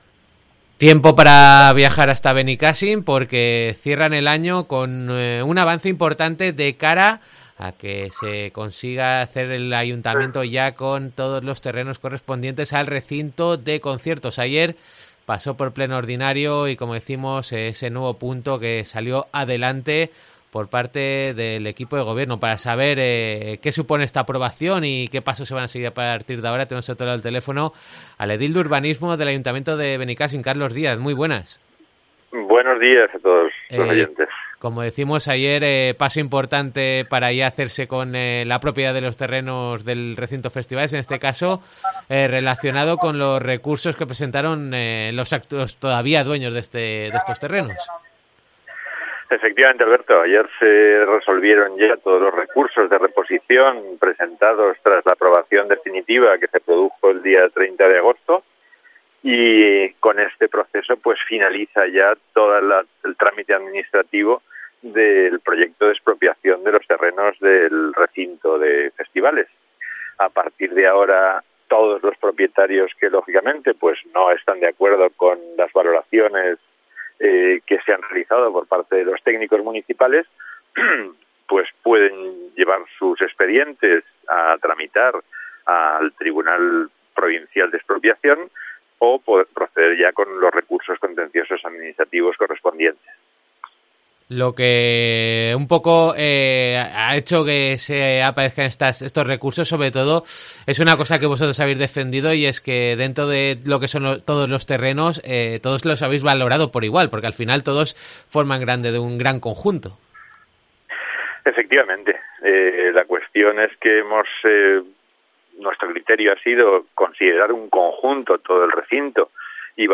Entrevista a Carlos Díaz, concejal de Urbanismo del ayuntamiento de Benicàssim